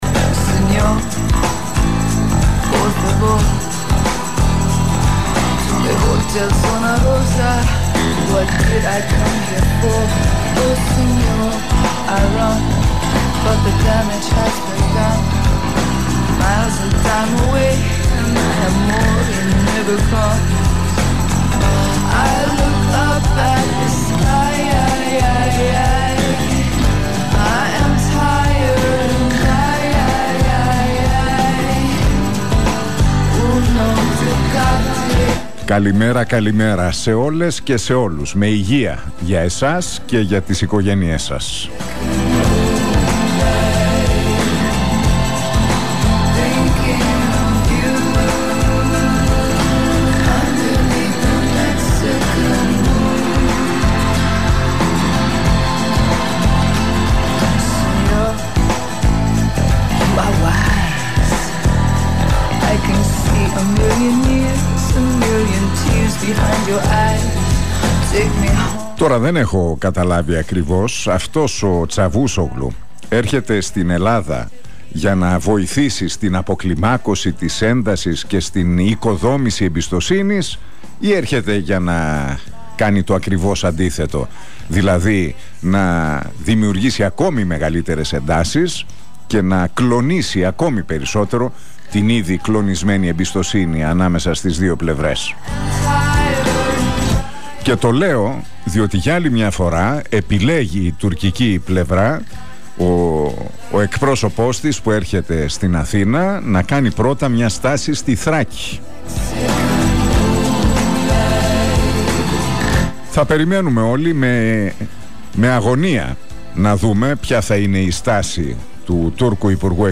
Ακούστε το σημερινό σχόλιο του Νίκου Χατζηνικολάου στον Realfm 97,8...